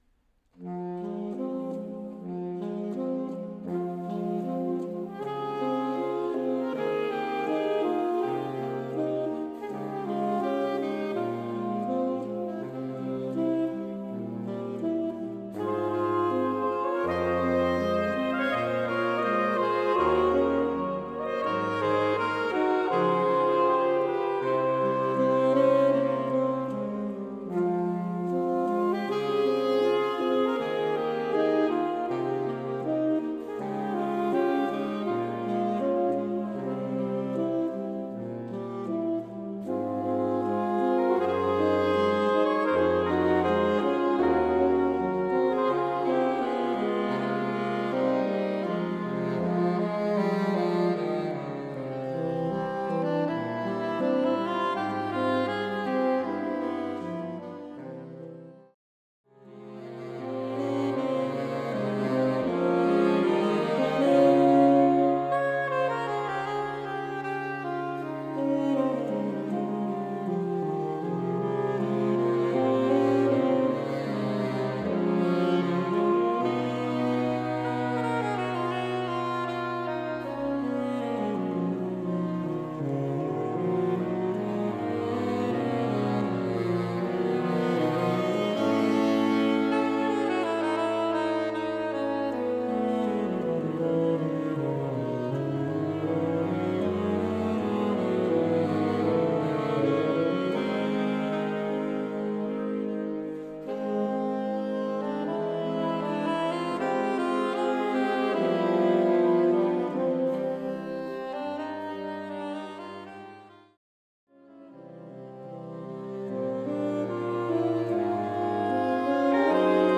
Lilienthaler Saxophon-Quartett
Einige Titel sind mit Hörbeispielen hinterlegt (MP3), die mit kleiner Technik und live aufgenommen wurden. Diese Beispiele sind durch Ein- bzw. Ausblendungen gekürzt.
Klassisches (E-Musik)